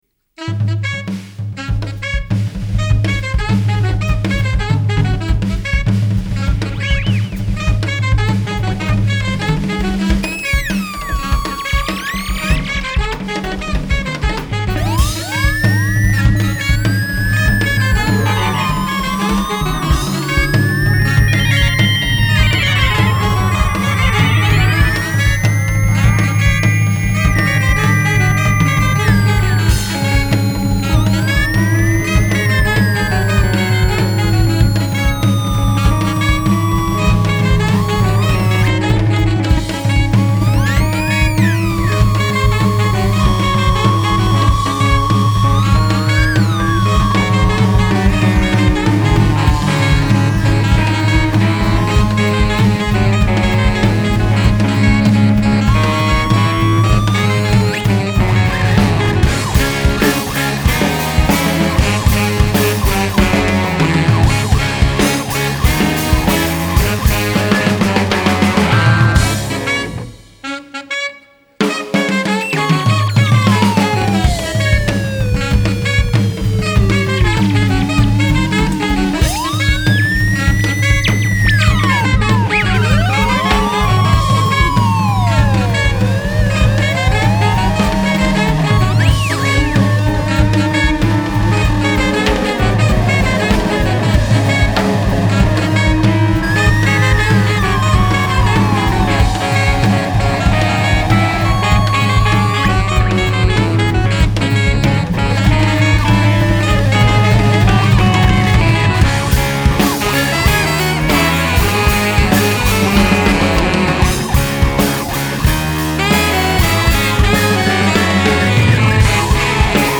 UK Jazz
New studio album
drums
saxophone
double bass
keyboards/ piano